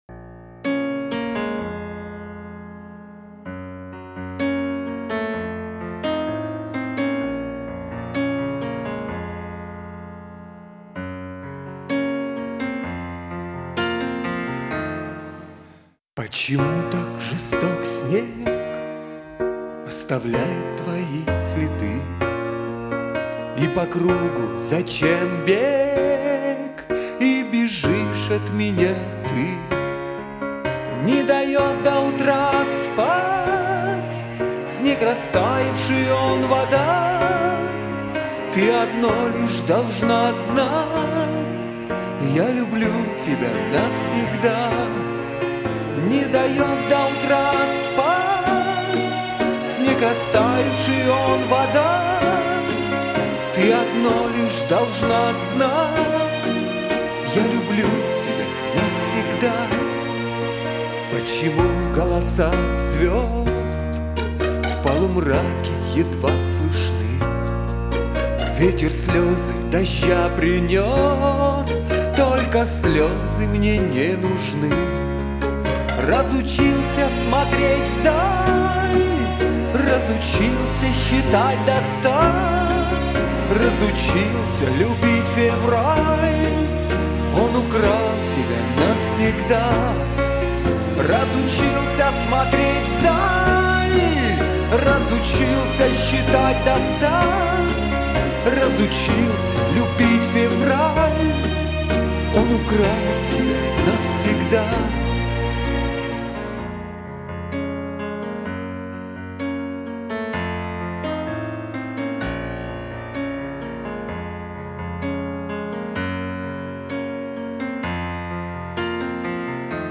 Акценты расставлены... Исполнение сильнее!